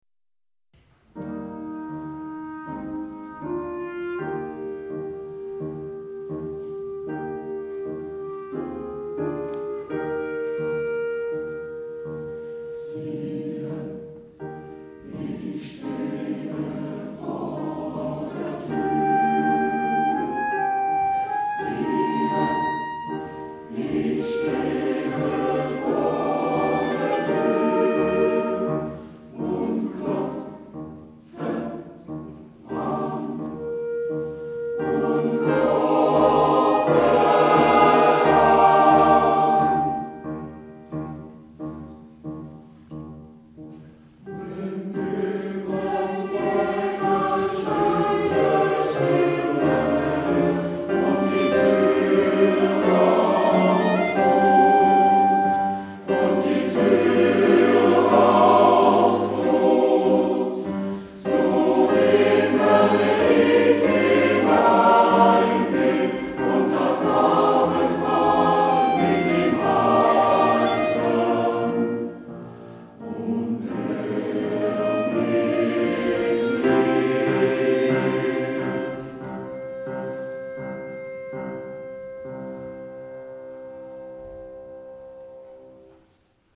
Vorweihnachtliches Singen und Musizieren
Mit beschaulichen Impulsen begann vom Frohsinn Rot in der Adventskantate Machet die Tore weit von Klaus Heizmann.
Trompete
Posaune
Klarinette
Querflöte
Klavier